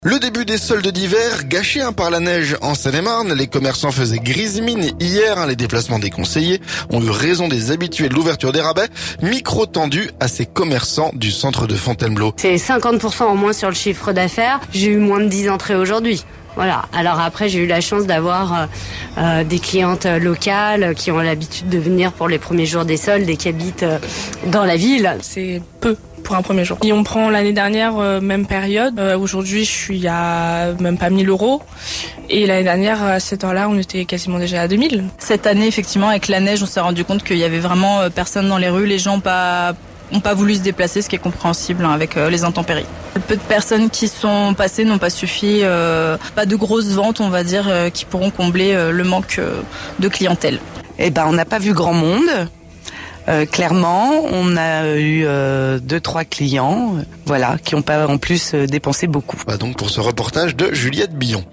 FONTAINEBLEAU - La neige a gâché le premier jour des soldes, reportage en centre-ville
Micro tendu à ces commerçants du centre de Fontainebleau.